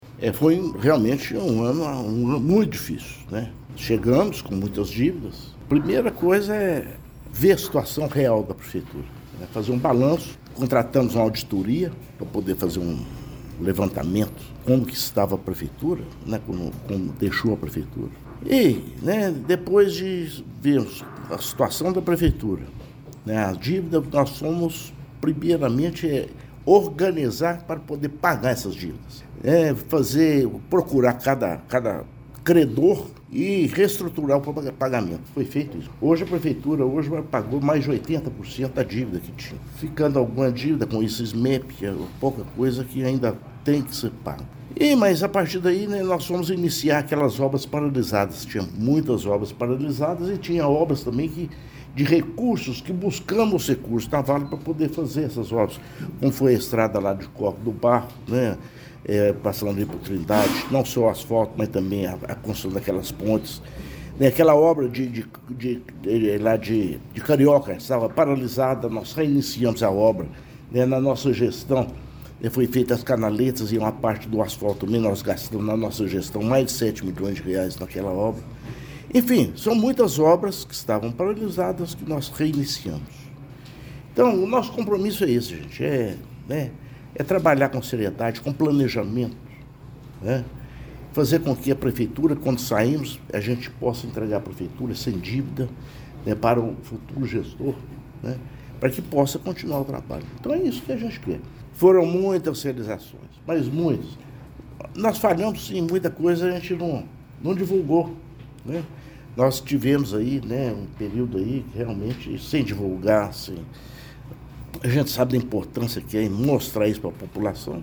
A Prefeitura de Pará de Minas apresentou, nesta segunda-feira, 22 de dezembro, um panorama das principais ações desenvolvidas ao longo de 2025, marcando o primeiro ano da atual administração.
O balanço teve como foco mostrar à população os resultados alcançados ao longo do ano, mesmo diante de um cenário desafiador herdado no início do mandato, como afirmou o prefeito: